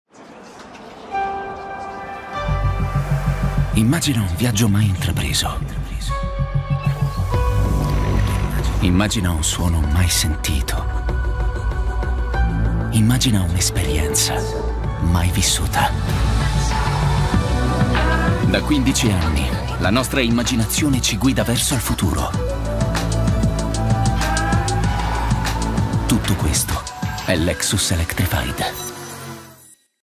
Età vocale: 25 - 45 anni
SPOT E PROMO AUDIO